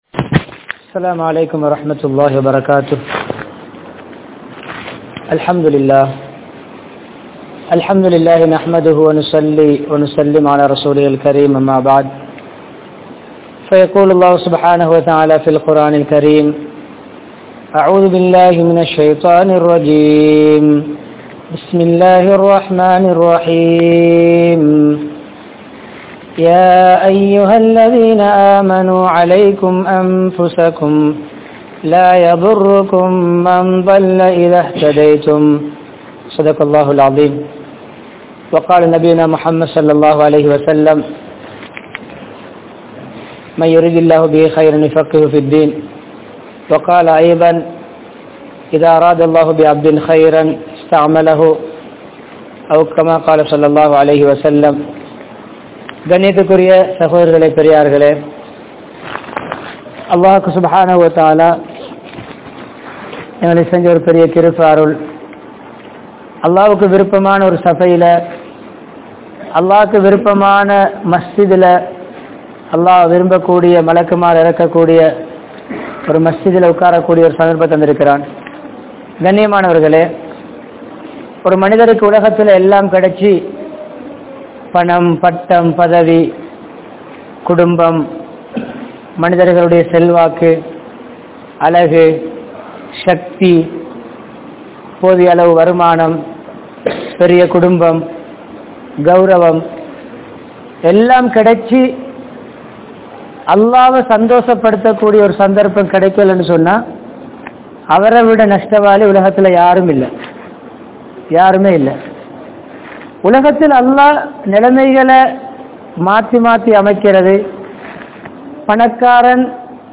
Thameerul Masjidhin Payankal | Audio Bayans | All Ceylon Muslim Youth Community | Addalaichenai
Kinniya, Faizal Nagar, Koofah Masjidh